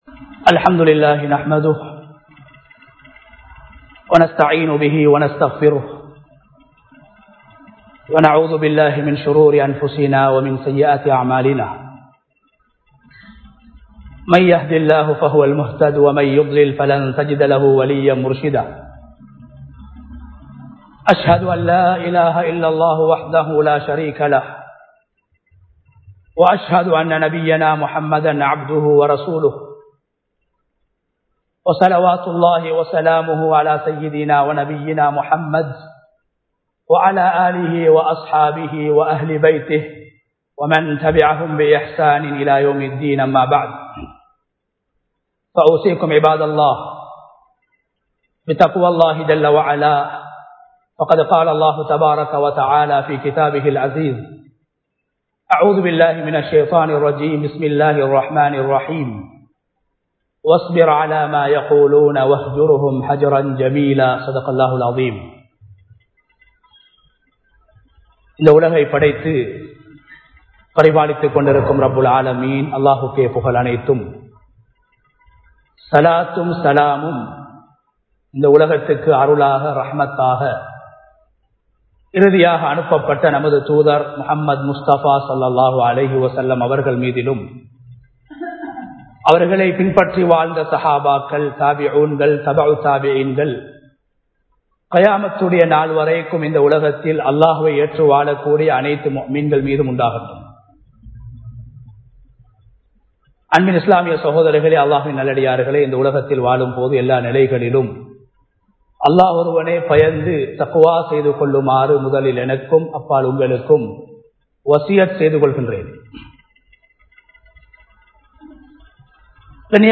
தவறுகளை திருத்துவோம் | Audio Bayans | All Ceylon Muslim Youth Community | Addalaichenai
Grand Jumua Masjitth